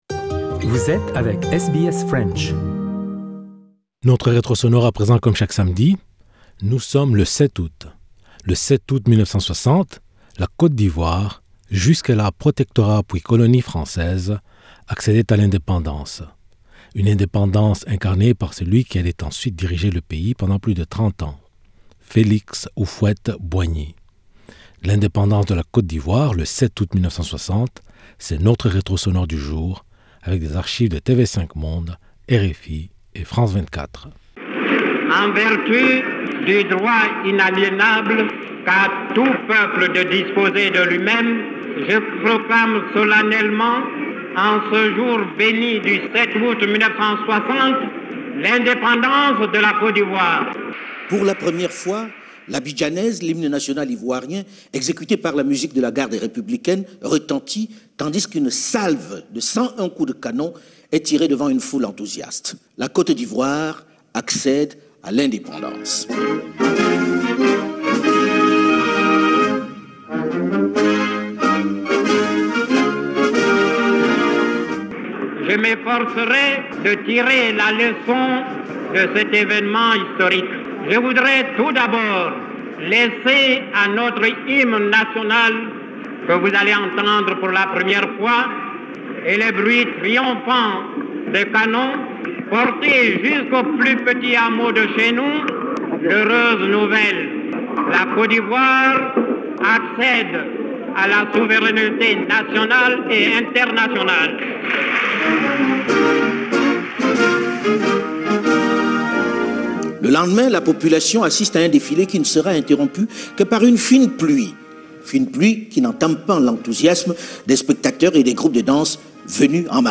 L'indépendance de la Côte d'Ivoire le 7 août 1960... c'est notre retro sonore du jour avec des archives de TV5 Monde, RFI et France24.